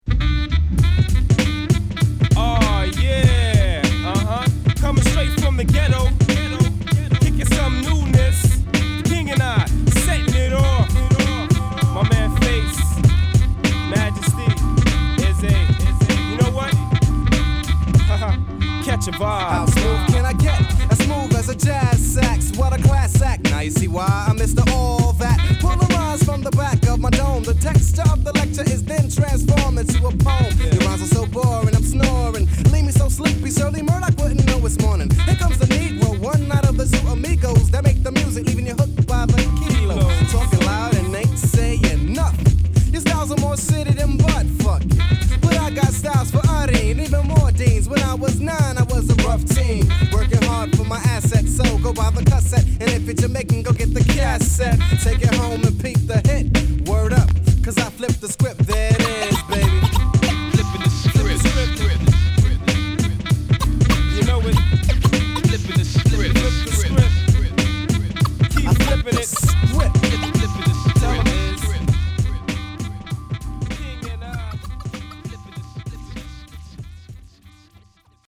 ジャジーで骨太なクラシック！